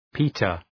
Προφορά
{‘pi:tər}